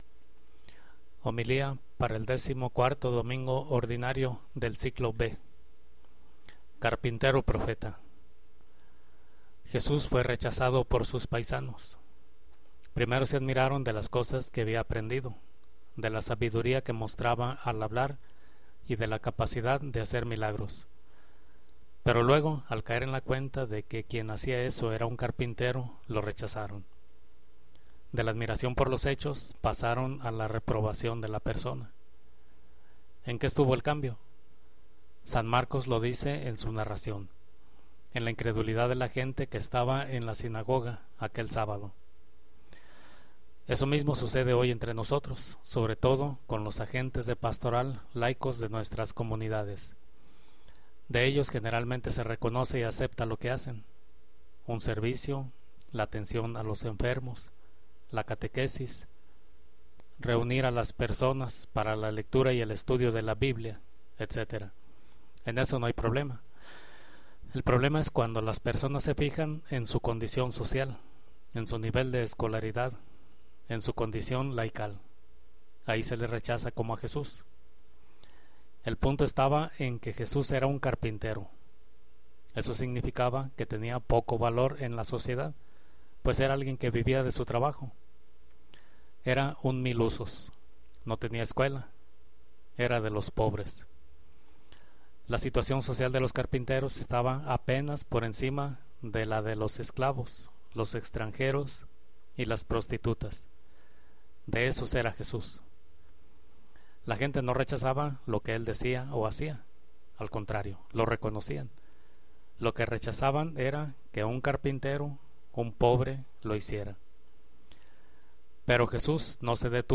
Homilía del 14º domingo ordinario 2012